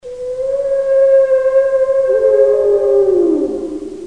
鲸鱼幽鸣的声音_爱给网_aigei_com.mp3